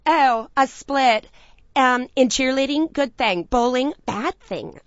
split_cheergood_bowlbad.wav